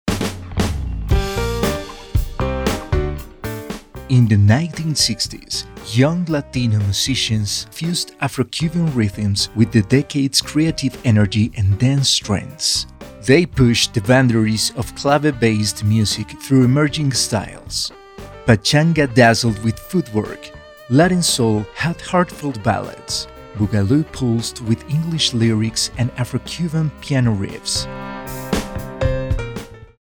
Demo comercial
Mi voz es un lienzo sonoro expresivo y versátil, capaz de transformarse en cada proyecto. Poseo un timbre cálido, claro y agradable, con una resonancia natural que proyecta confianza y profesionalismo.
Mi dicción es impecable, asegurando que cada palabra resuene con claridad, mientras que mi capacidad interpretativa me permite infundir autenticidad y emoción, ya sea transmitiendo credibilidad para una marca institucional, pasión por una historia o la intensidad requerida en el doblaje de anime y videojuegos.
Presonus Studio 24C, previo CODA STEALTH, sala insonorizada y acústica